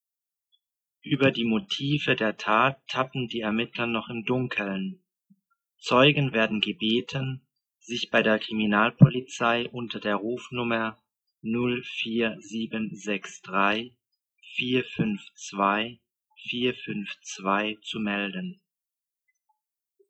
Aussprache: einen Text lesen